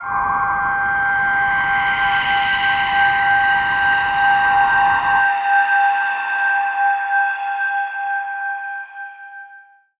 G_Crystal-G6-f.wav